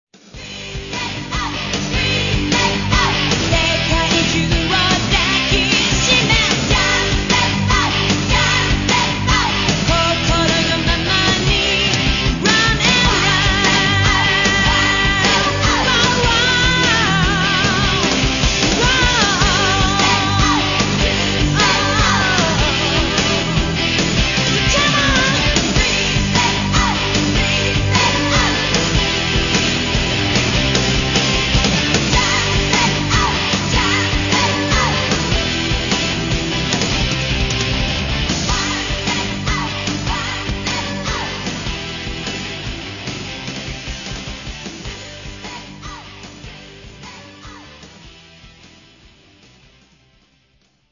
The outro